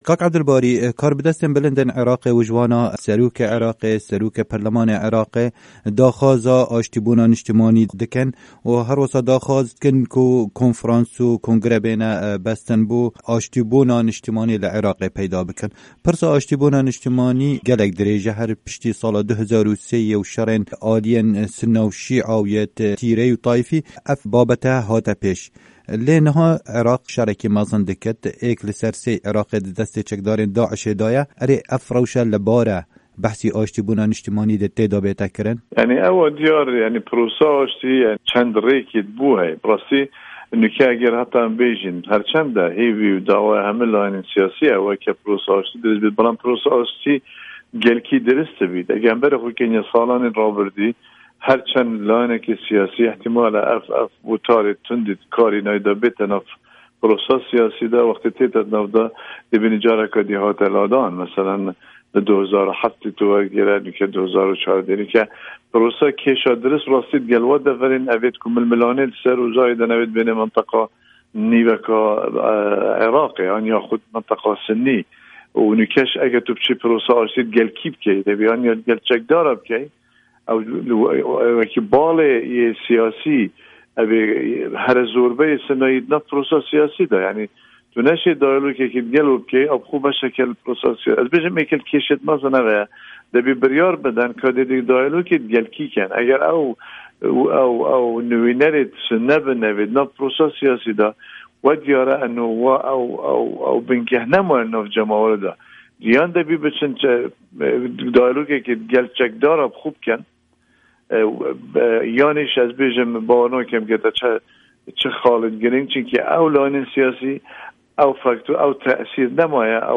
hevpeyvin digelEbdulbarî Zêbarî